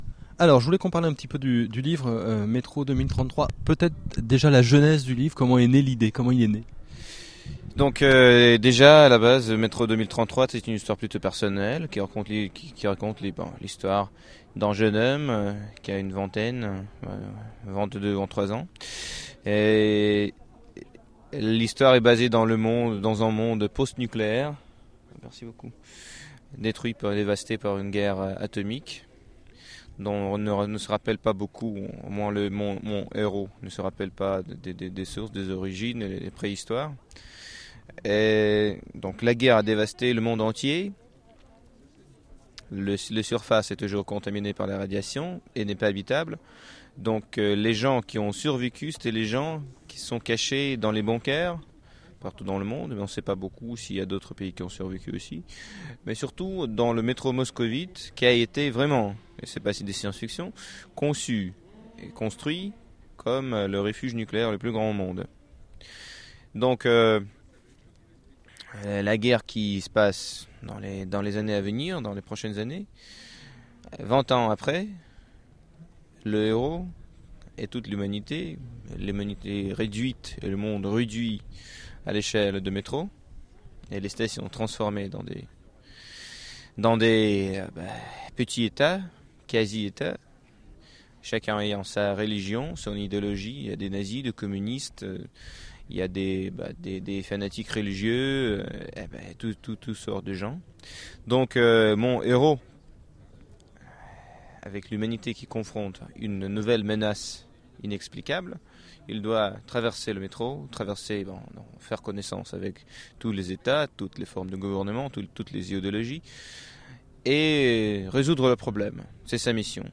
Voici une interview audio réalisée aux Etonnants Voyageurs de Dmitry Glukhovsky en 2010, alors que les éditions l'Atalante venaient de publier Métro 2033 .